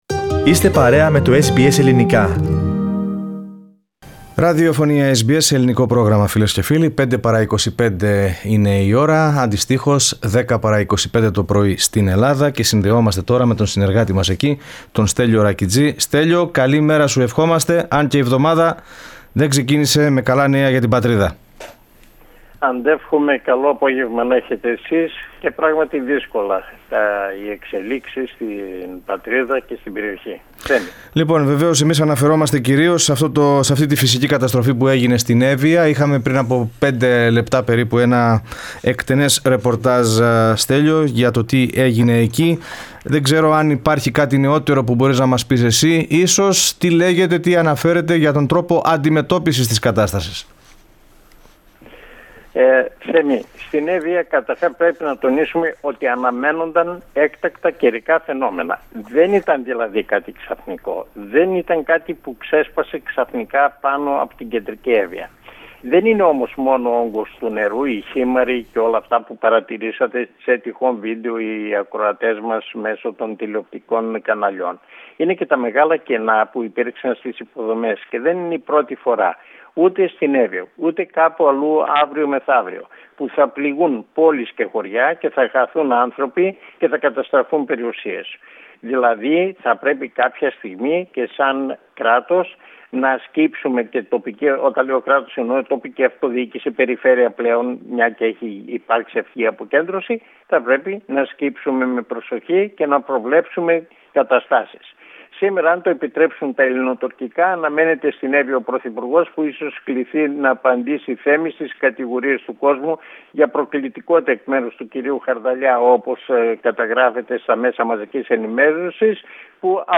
The natural disaster in Evia with seven deaths, the coronavirus, which is galloping in Greece with more than 200 cases yesterday, the controversy over whether the Greek-Egyptian agreement on their Exclusive Economic Zone and Turkey's reaction to this development, are the main issues of the report from Greece for this week.